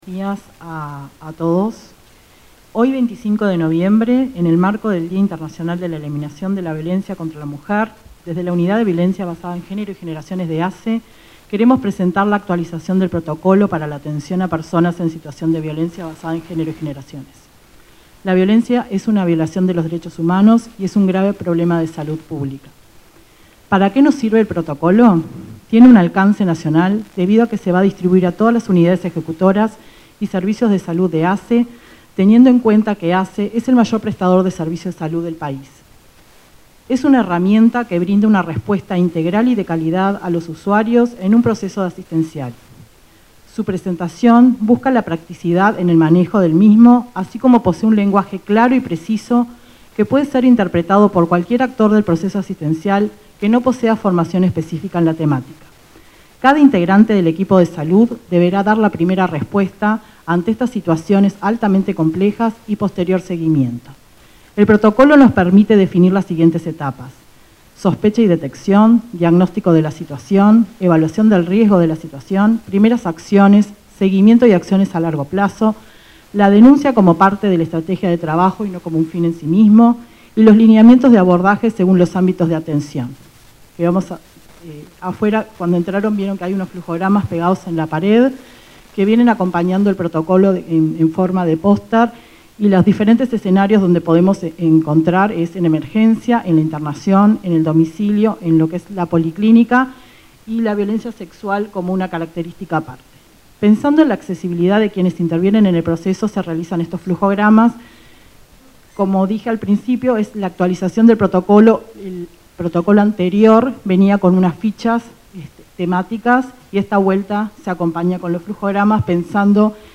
Conferencia de prensa de ASSE por protocolo actualizado para atender a personas en situación de violencia de género
Conferencia de prensa de ASSE por protocolo actualizado para atender a personas en situación de violencia de género 25/11/2021 Compartir Facebook X Copiar enlace WhatsApp LinkedIn Este 25 de noviembre, Día Internacional de la Eliminación de la Violencia contra la Mujer, la Administración de los Servicios y Salud del Estado (ASSE) presentó la actualización de un protocolo que servirá de guía a los equipos de salud. Participaron del evento, Lorena Ponce de León y el presidente de ASSE, Leonardo Cipriani, entre otras autoridades.